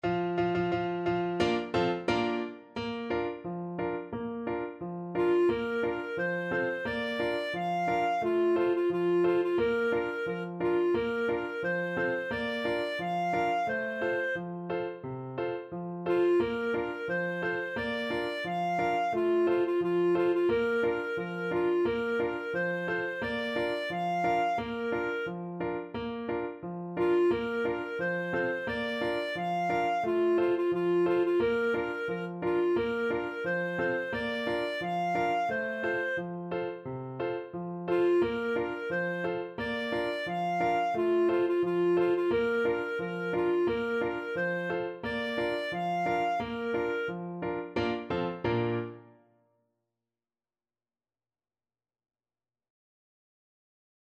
2/4 (View more 2/4 Music)
Steady march =c.88
Swiss